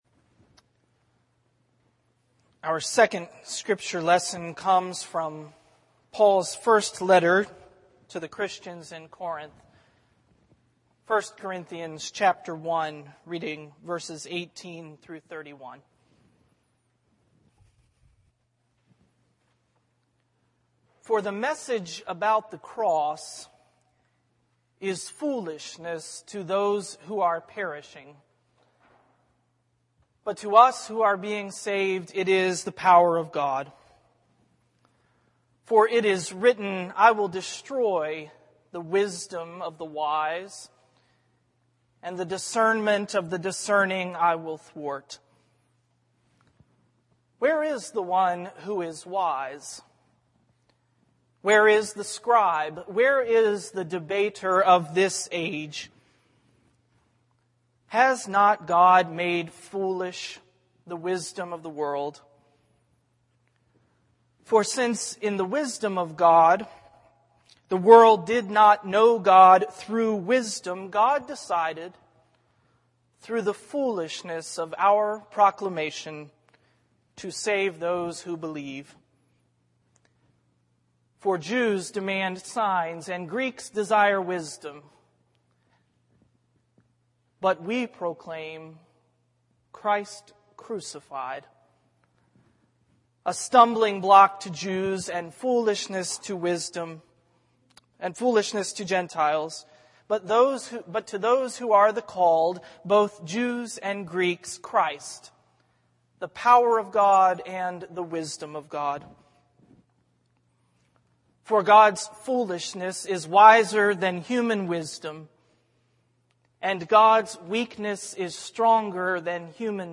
THE SERMON People Want to Know - A Sermon Series for Lent IV. “ Why a Crucified Savior? ”
worship_feb17sermon.mp3